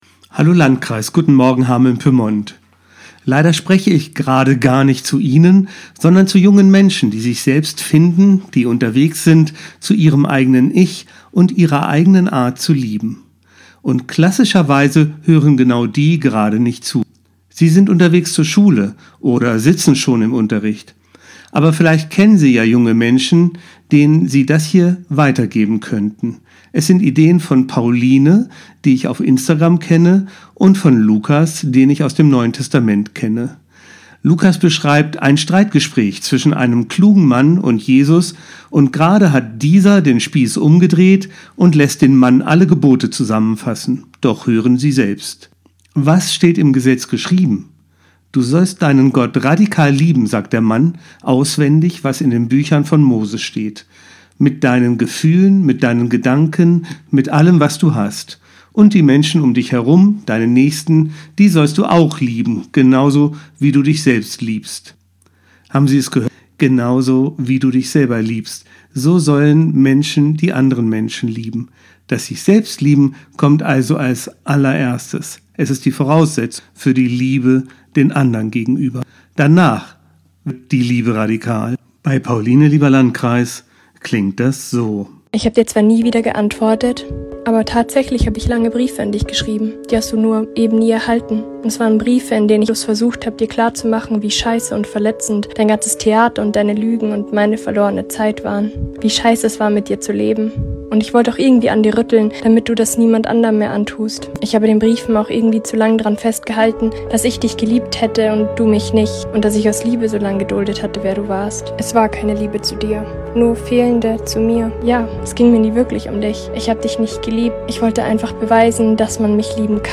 Radioandacht vom 21. März